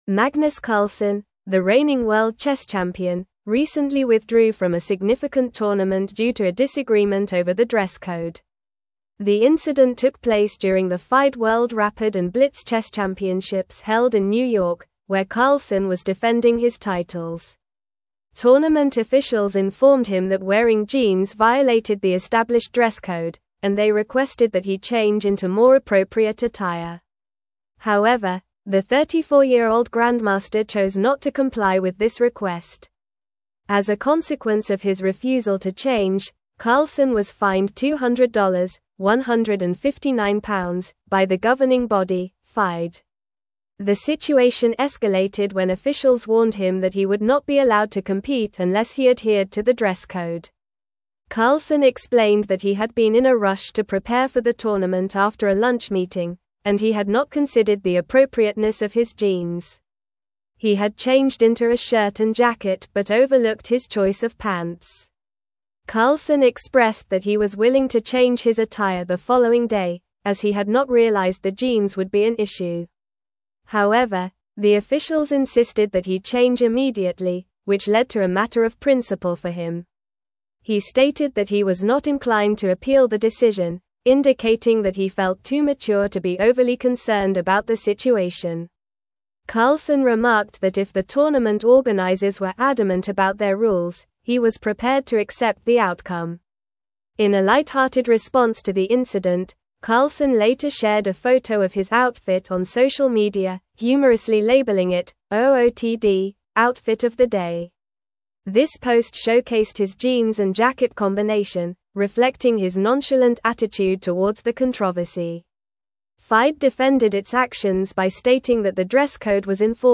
Normal
English-Newsroom-Advanced-NORMAL-Reading-Carlsen-Withdraws-from-Tournament-Over-Dress-Code-Dispute.mp3